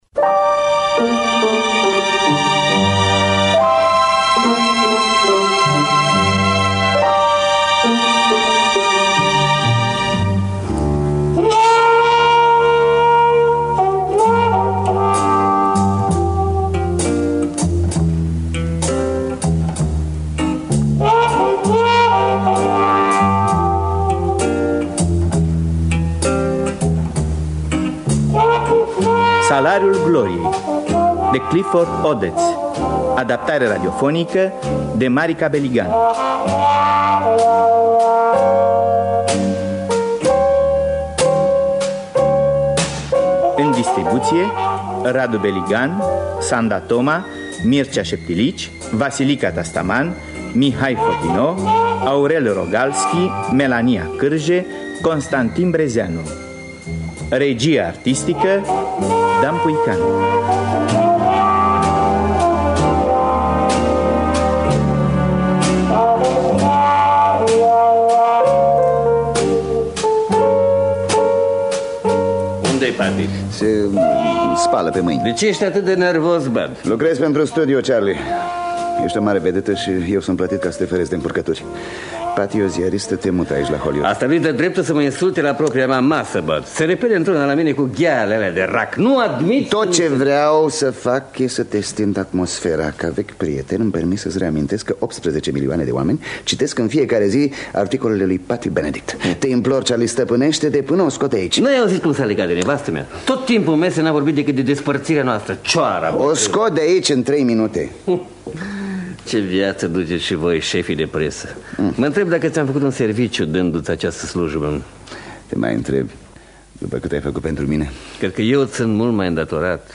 Adaptarea radiofonică